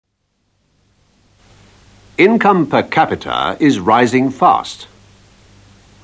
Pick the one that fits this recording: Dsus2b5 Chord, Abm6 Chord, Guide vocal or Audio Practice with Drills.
Audio Practice with Drills